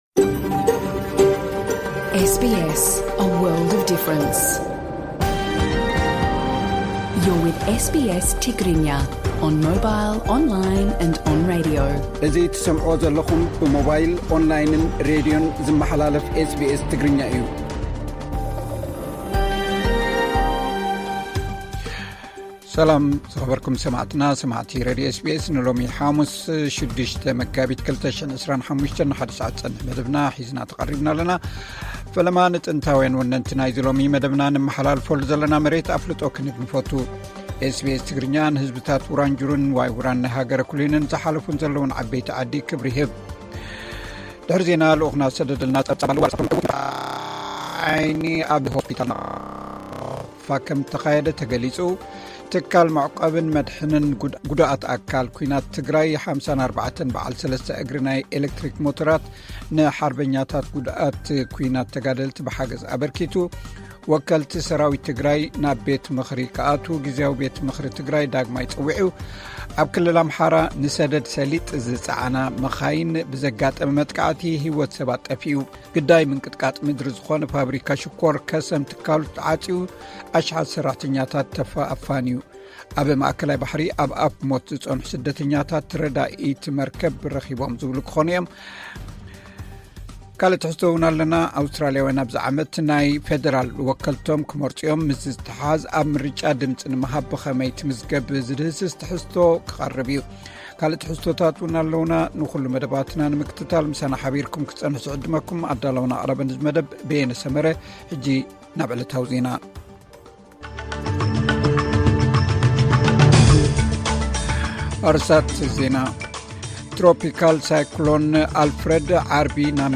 ቀጥታ ምሉእ ትሕዝቶ ኤስ ቢ ኤስ ትግርኛ (06 መጋቢት 2025)